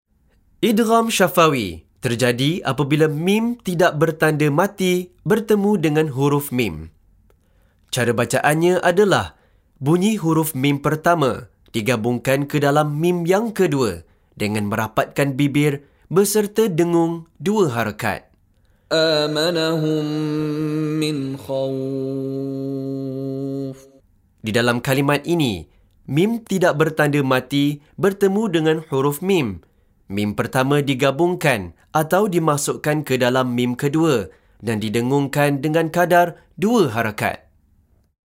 Contoh Bacaan dari Sheikh Mishary Rashid Al-Afasy
Bunyi Huruf Mim Pertama DIGABUNGKAN ke dalam sebutan huruf Mim Kedua beserta dengung 2 harakat.